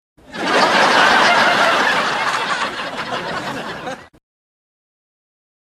Laughs 7 BIG